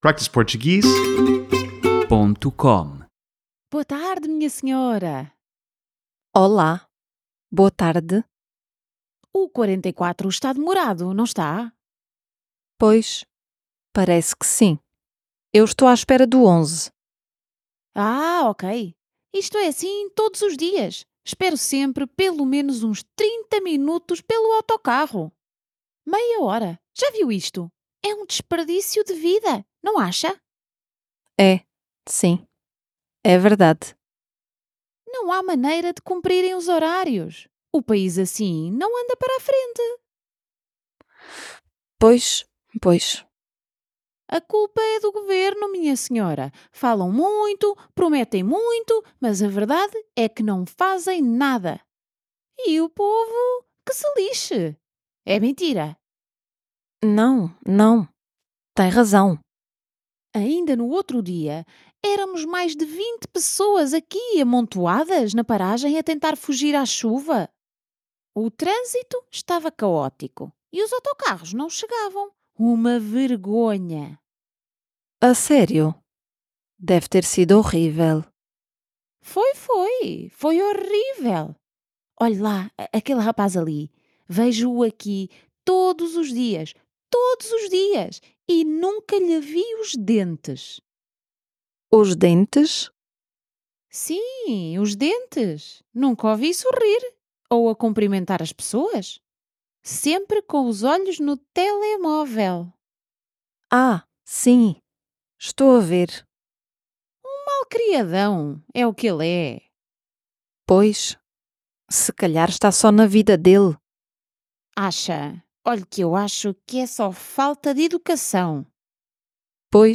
Caught at a bus stop with a talkative stranger, Ana patiently contributes to the one-sided chat using simple comments that say "I'm listening", without